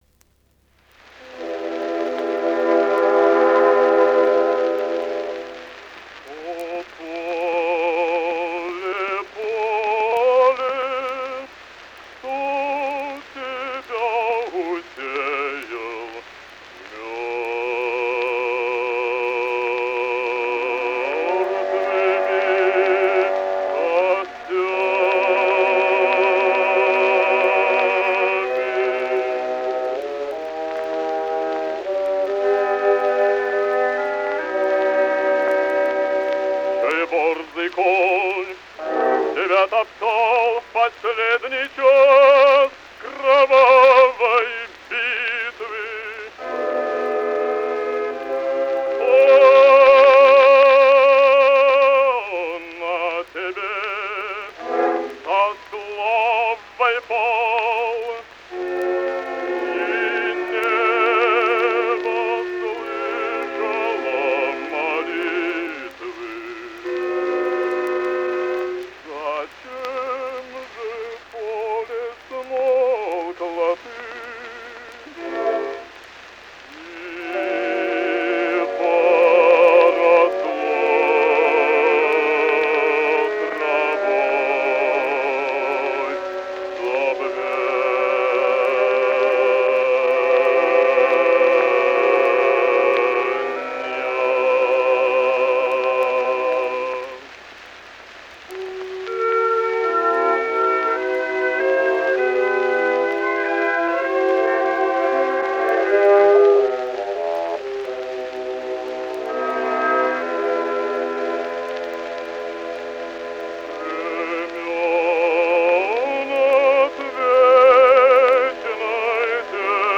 Опера «Руслан и Людмила». Ария Руслана. Исполняет В. И. Касторский.